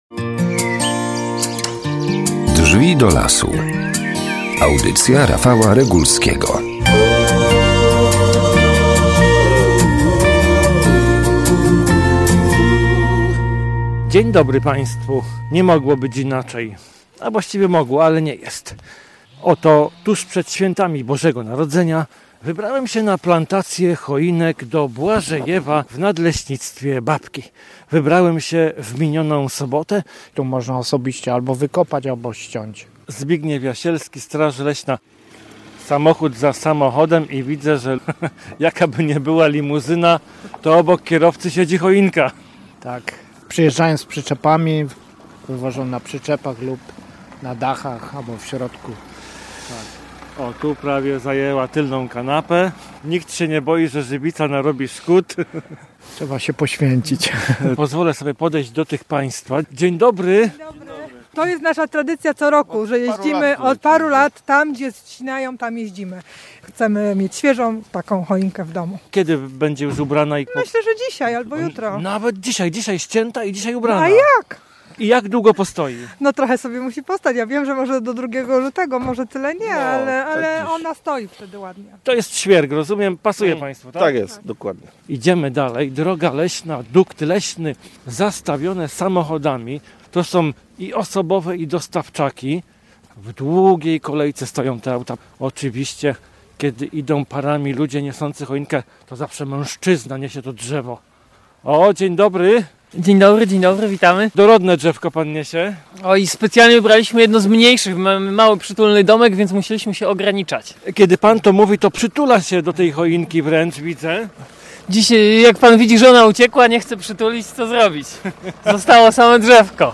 I do lasu zaglądamy po to, aby takie oblężenie pokazać. Mikrofonem oczywiście.